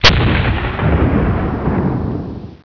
Bang5
BANG5.WAV